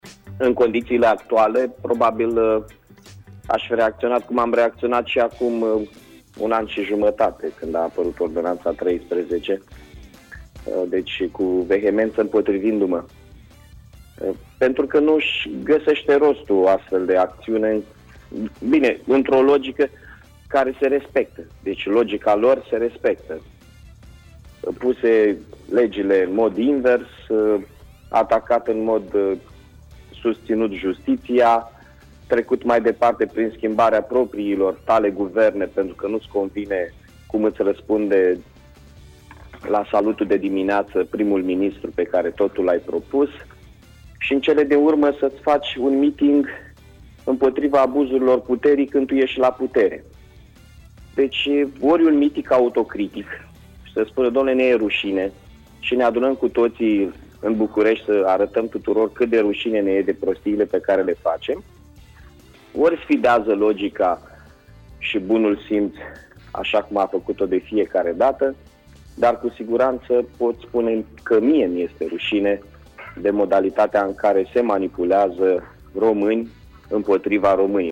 Primarul Mihai Chirica a declarat, în această dimineață la Radio HIT că dacă ar fi fost primar în PSD și i s-ar fi cerut să aducă oamenii cu forța la miting s-ar fi împotrivit.